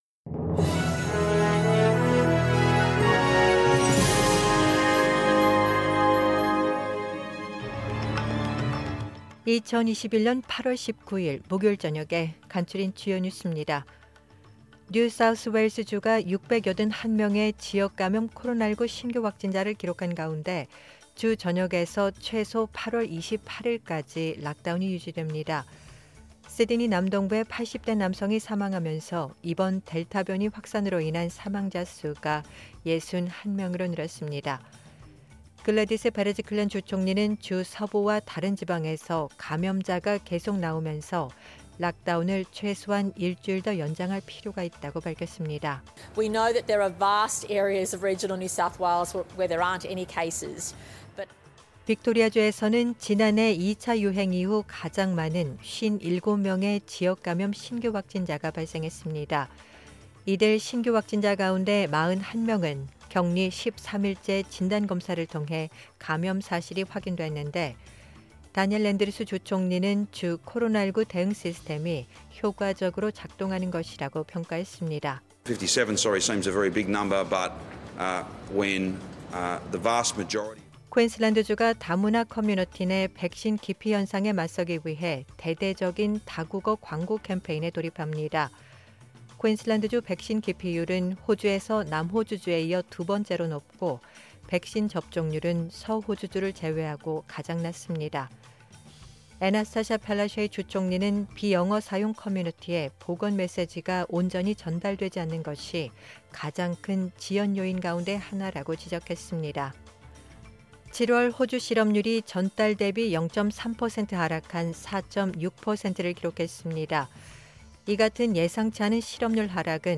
2021년 8월 19일 목요일 저녁의 SBS 뉴스 아우트라인입니다.